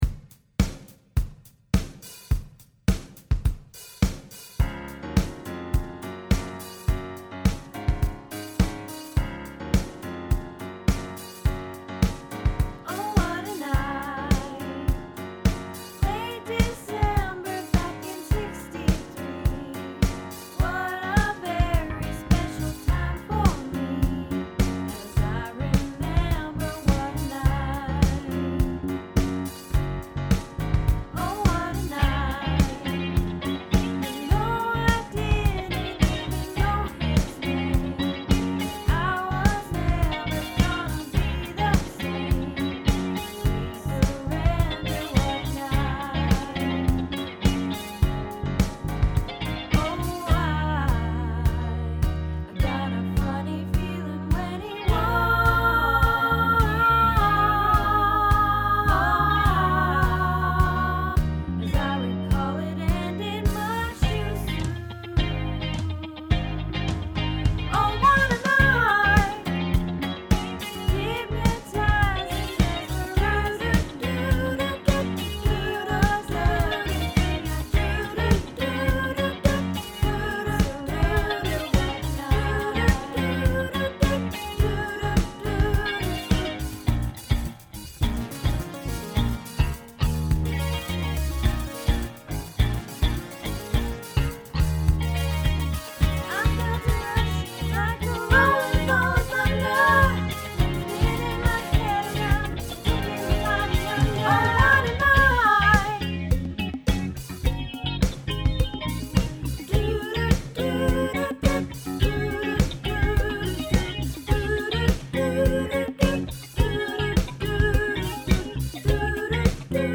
Oh What A Night - Soprano